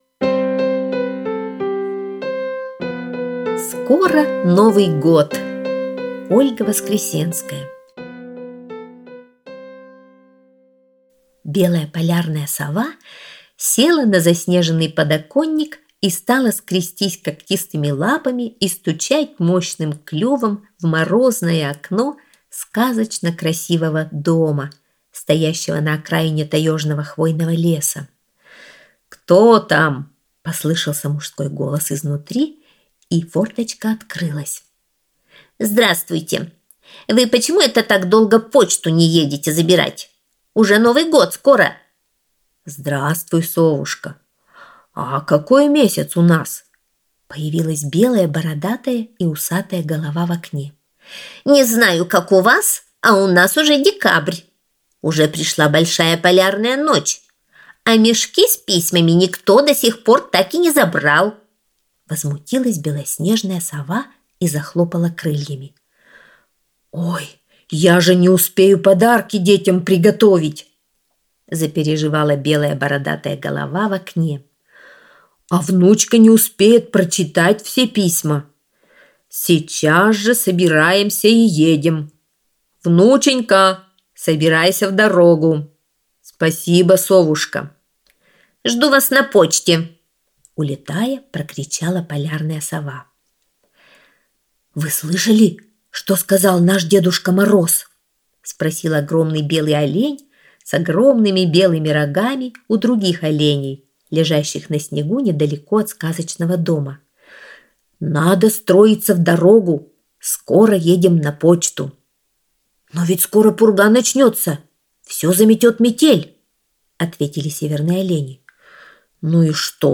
- аудиосказка Воскресенской - слушать